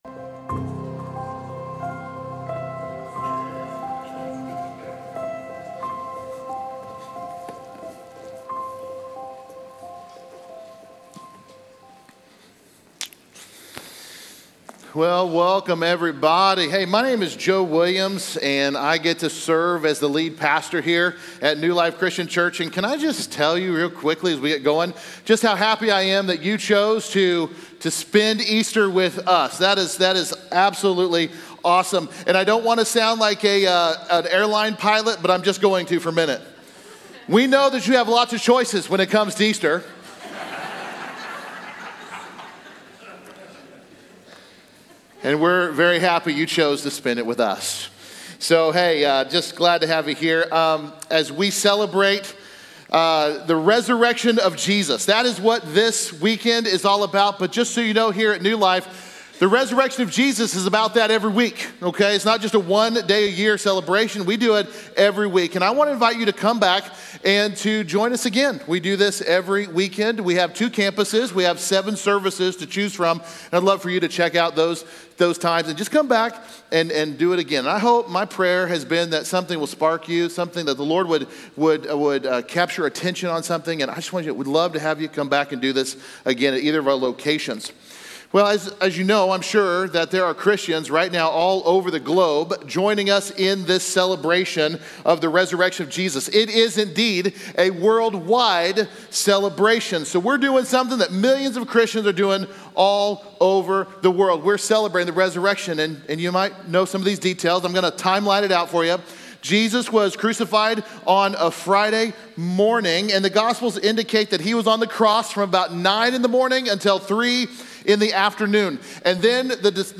Join us for an uplifting Easter service as we celebrate the resurrection of Jesus and explore the powerful story of Peter's transformation. Discover how the hope of the empty tomb brings new beginnings and second chances for us all!